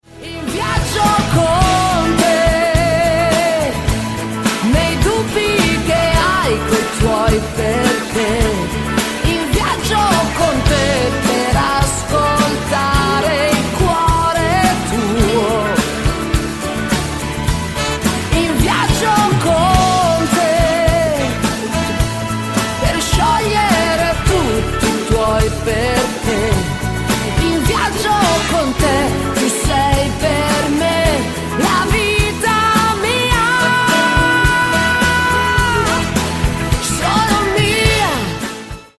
MODERATO  (4.59)